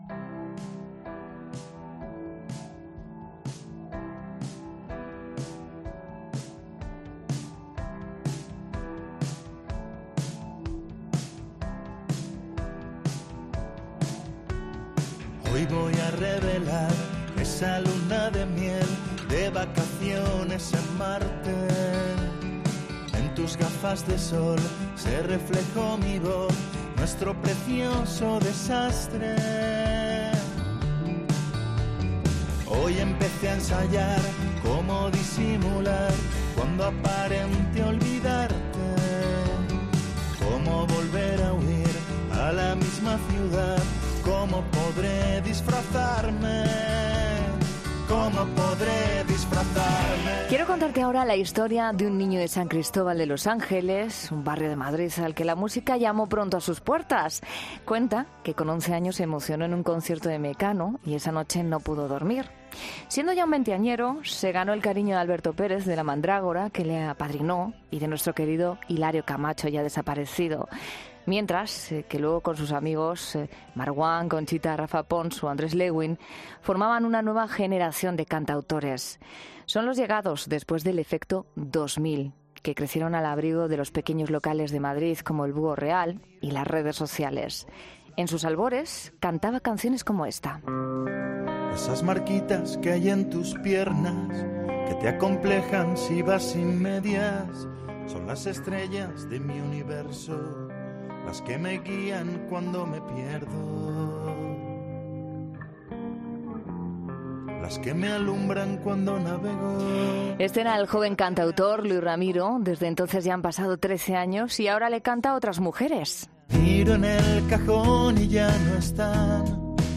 El artista madrileño se sincera ante los micrófonos de la Cadena COPE